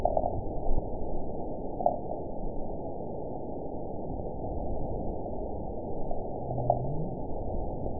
event 917119 date 03/20/23 time 20:40:17 GMT (2 years, 1 month ago) score 9.29 location TSS-AB03 detected by nrw target species NRW annotations +NRW Spectrogram: Frequency (kHz) vs. Time (s) audio not available .wav